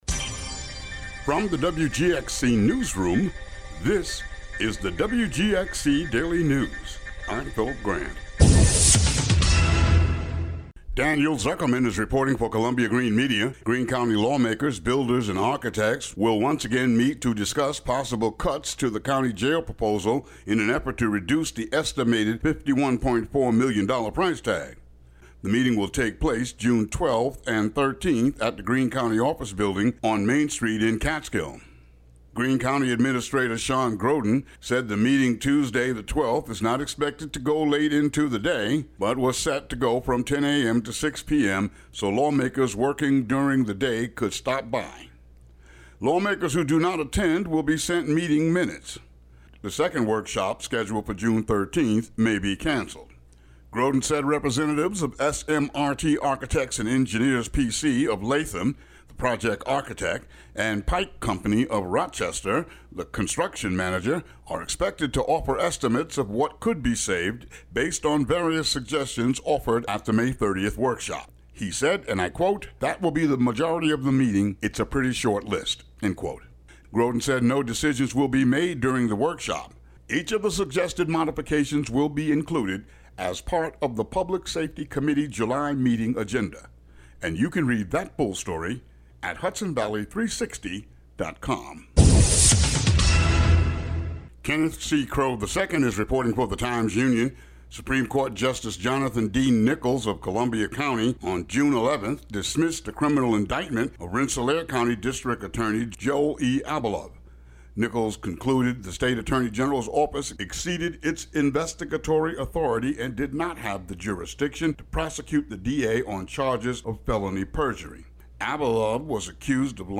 Daily headlines for WGXC.